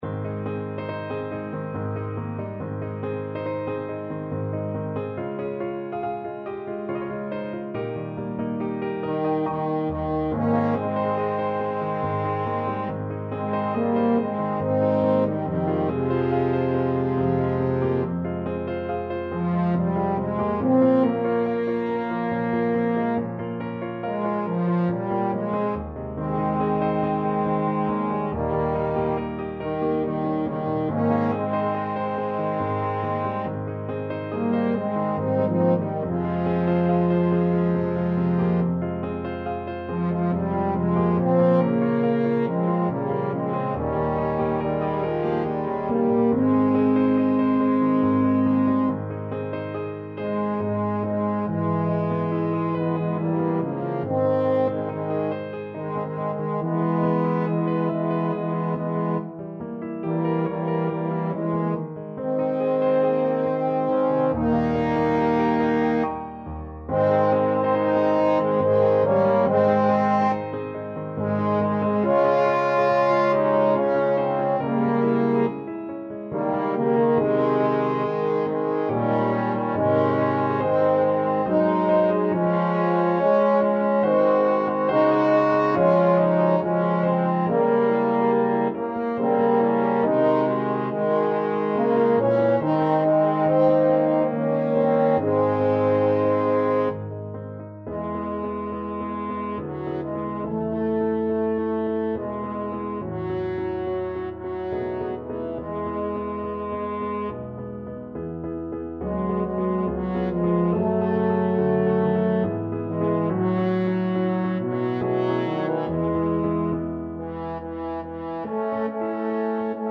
TBB male choir and piano
世俗音樂